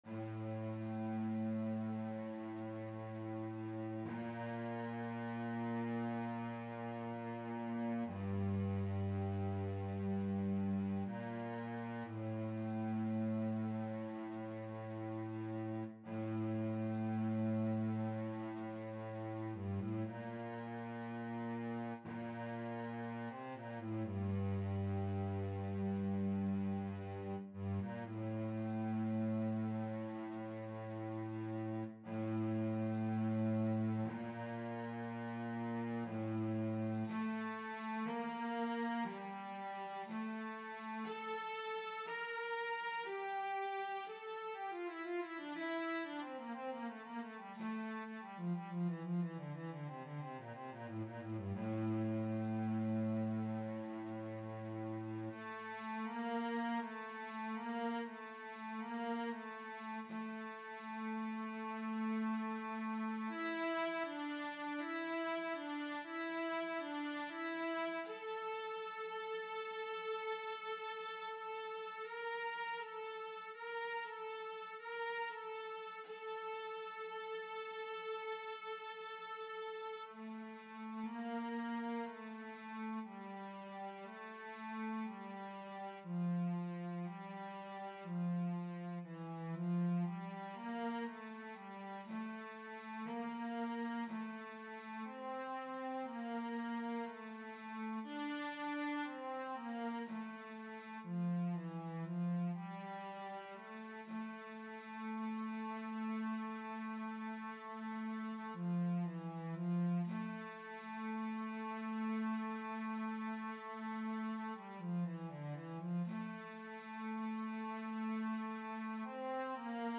or harp and cello.
Harp & Cello